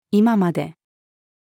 今まで-until-now-female.mp3